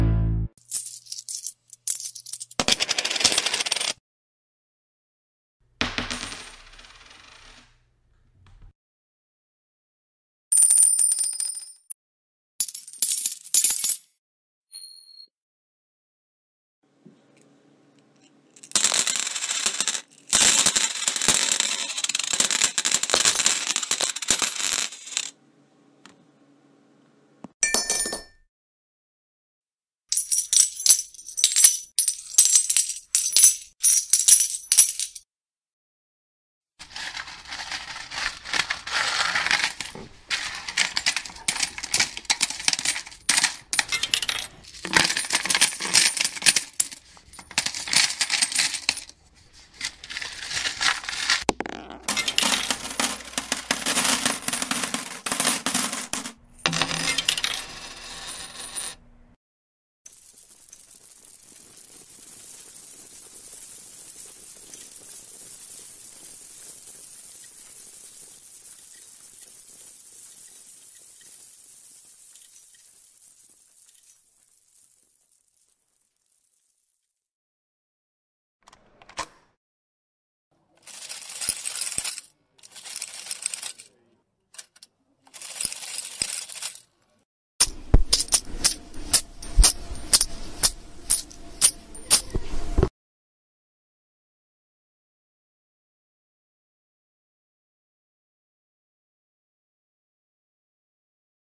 دانلود صدای افتادن یا ریختن سکه 3 از ساعد نیوز با لینک مستقیم و کیفیت بالا
جلوه های صوتی